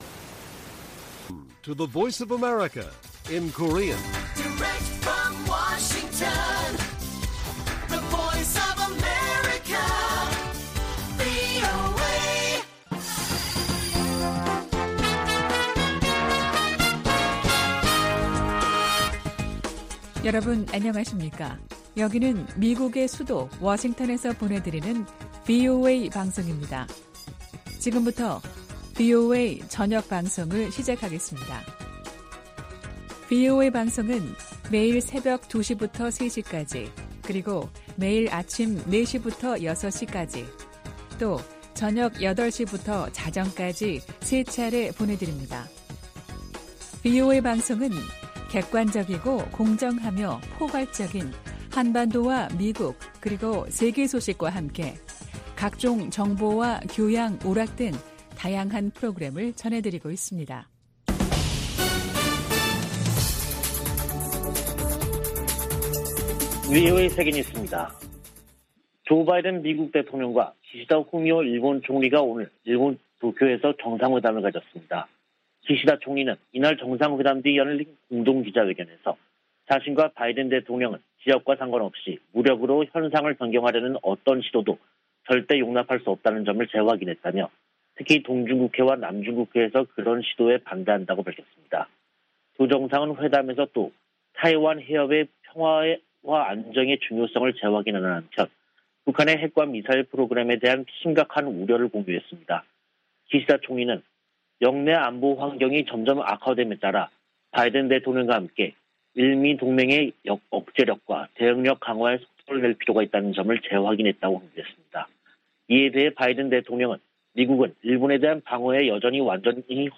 VOA 한국어 간판 뉴스 프로그램 '뉴스 투데이', 2022년 5월 23일 1부 방송입니다. 조 바이든 미국 대통령과 기시다 후미오 일본 총리는 북한의 핵과 탄도미사일 프로그램을 규탄했습니다. 바이든 대통령이 미국을 포함한 13개 국가가 참여하는 '인도태평양 경제프레임워크(IPEF)' 출범을 공식 선언했습니다. 워싱턴의 전문가들은 미한동맹이 바이든 대통령의 방한을 계기로 안보동맹에서 기술안보, 글로벌 동맹으로 진화했음을 보여줬다고 평가했습니다.